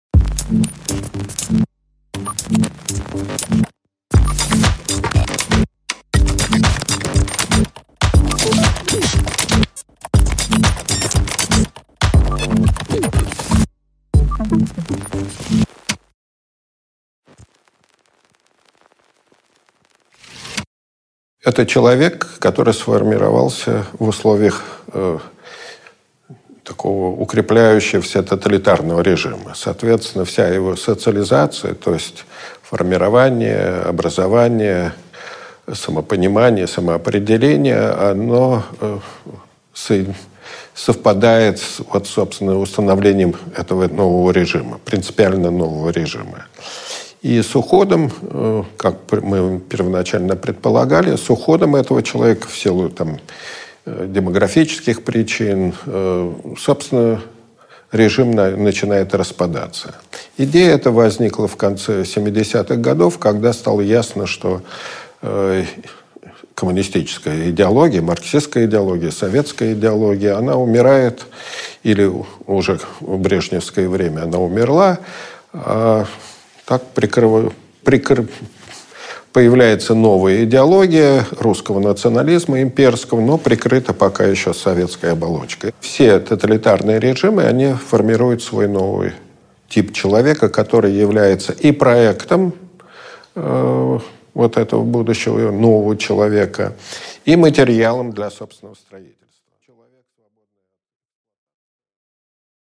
Аудиокнига Страх, бессилие и зависть | Библиотека аудиокниг